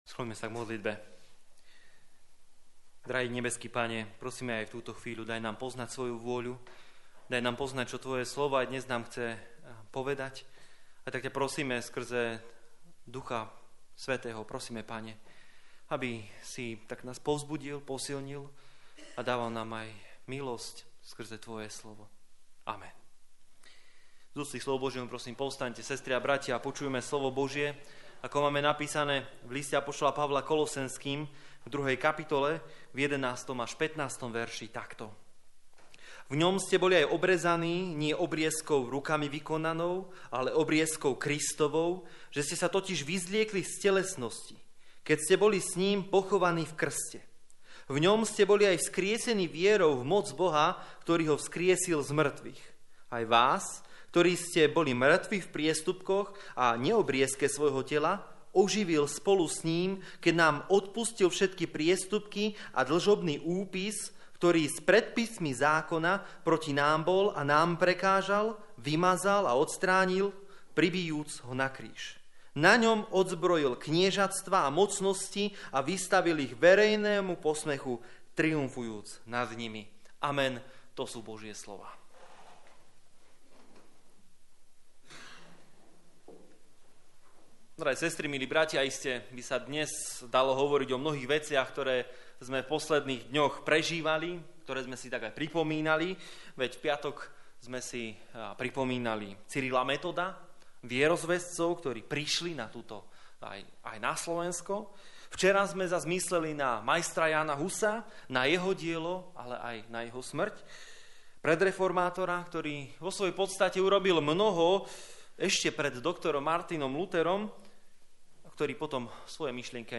15 Service Type: Služby Božie 3. nedeľa po Svätej Trojici « Boh pomáhal a bude i ďalej Postavme sa proti hriechu